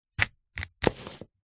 На этой странице собраны разнообразные звуки, связанные с манго: от мягкого разрезания ножом до сочного откусывания.
Звук разрезания манго ножом